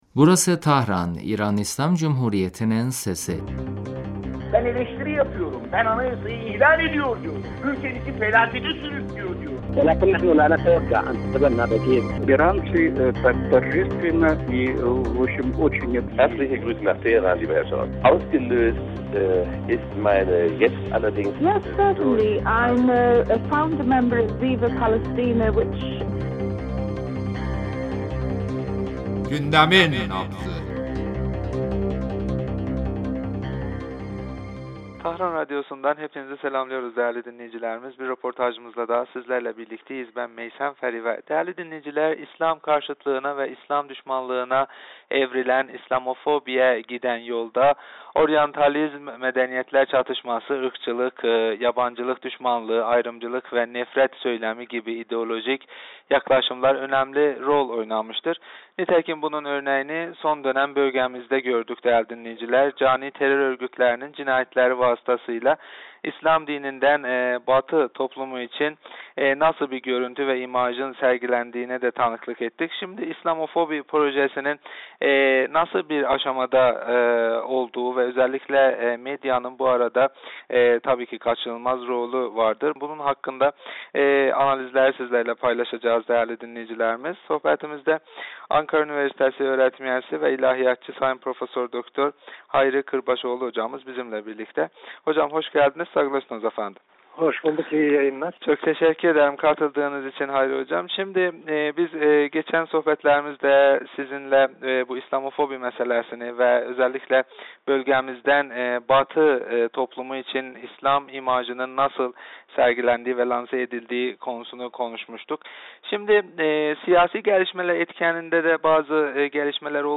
ile yaptığımız telefon görüşmesinde İslamofobi projesinde medyanın rolü üzerinde konuştuk.